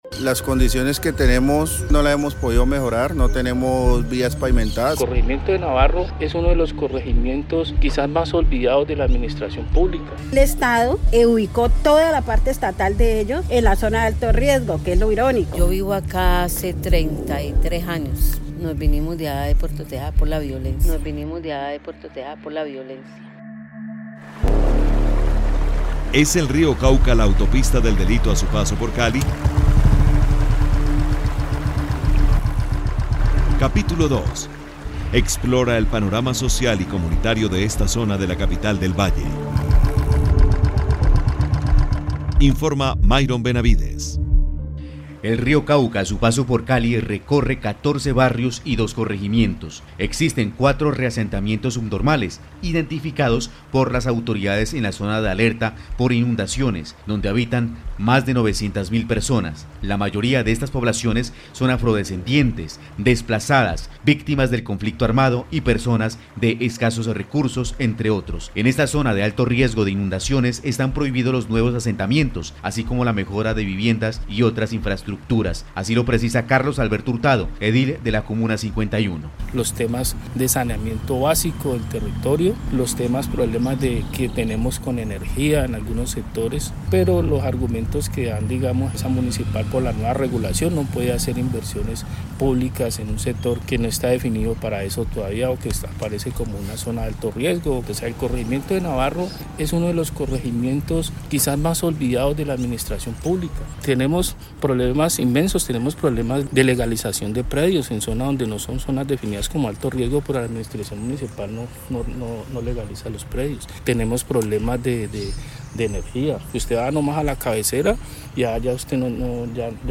Pasado y presente del río Cauca, con testimonios de la realidad del más importante cauce que cruza a Cali.